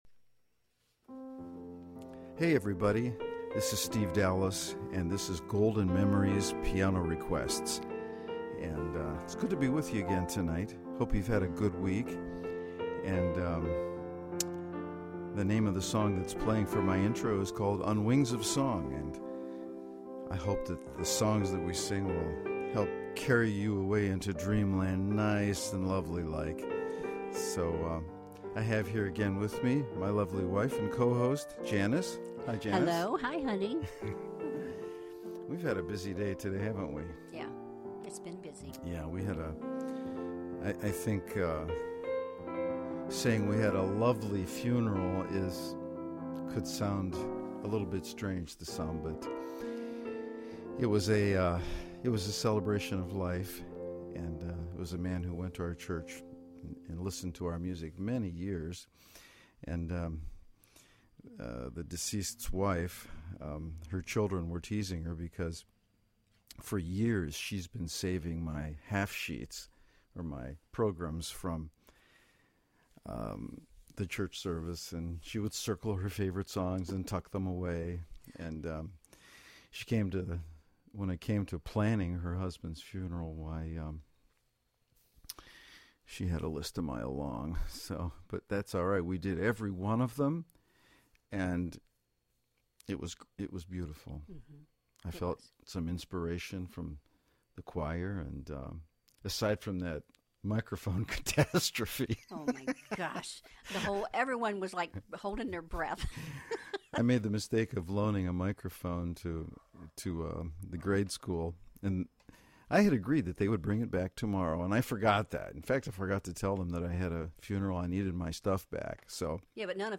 Golden oldies played to perfection!
And certainly give me a call with your song request (see listing of available numbers) and we’ll see if I can play it on the spot!